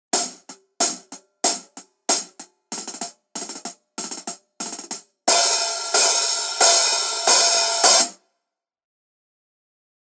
Тарелки серии Custom обладают широким частотным диапазоном, теплым плотным звуком и выдающейся музыкальностью.
Masterwork 14 Custom Hats sample
Custom-Hihat-14.mp3